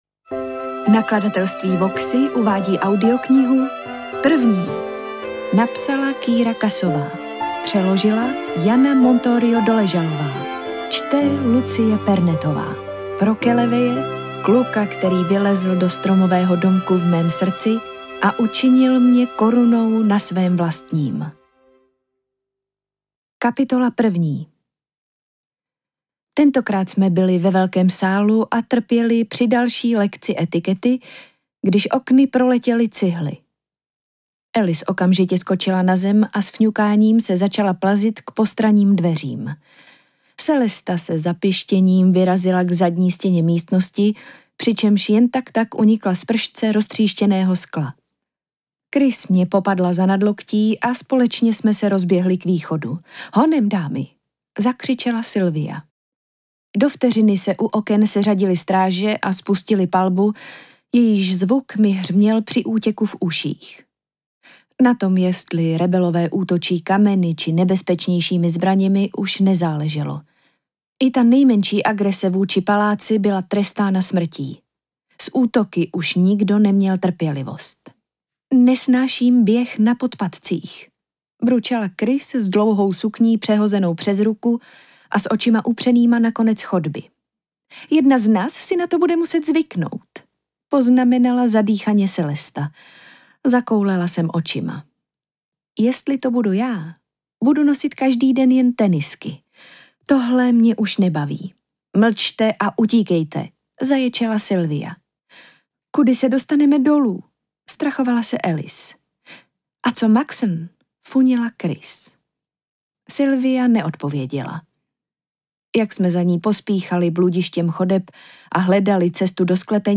Interpret:  Lucie Pernetová
AudioKniha ke stažení, 33 x mp3, délka 9 hod. 55 min., velikost 543,1 MB, česky